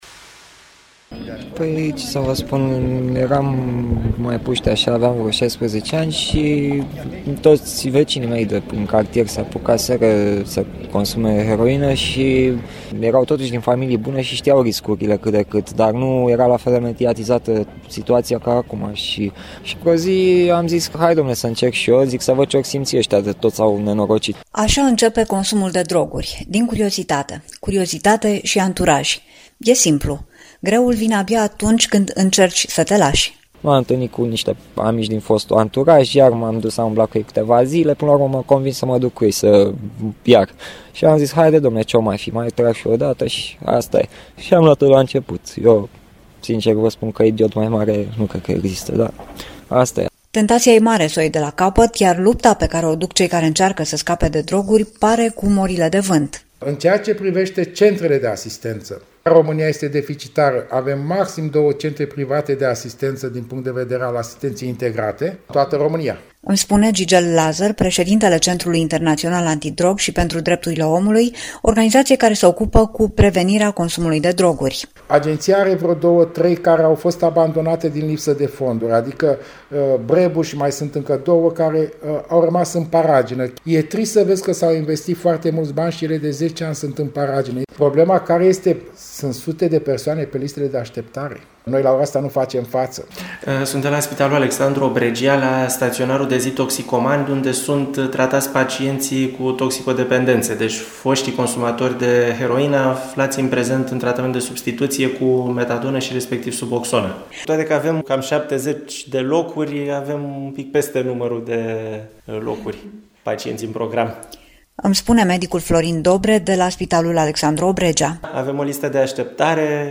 REPORTAJ: Strategia Antidrog – sublimă, dar lipseşte cu desăvârşire